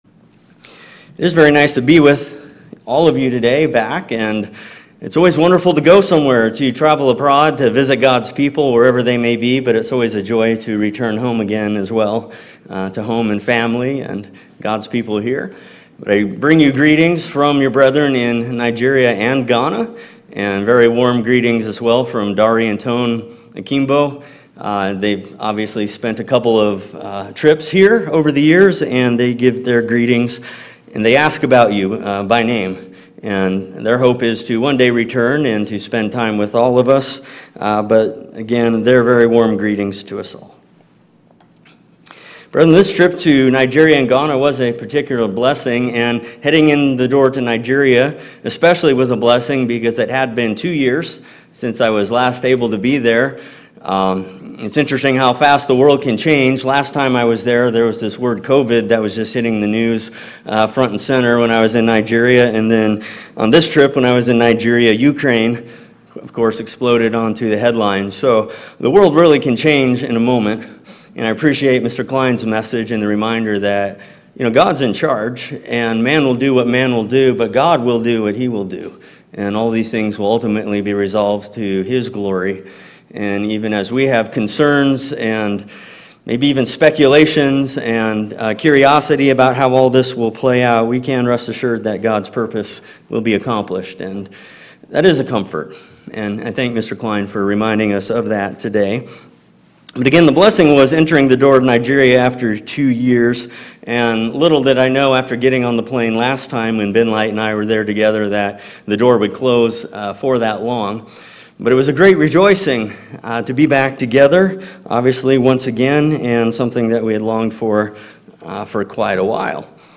There are many character traits that we are unable to develop on our own; being in the assembly provides opportunity for our growth. The assembly also provides us opportunities to serve one another, which not only benefits those being served, but those doing the serving. This sermon highlights his recent visit to Nigeria, with 130 members, and Ghana, with 265 members.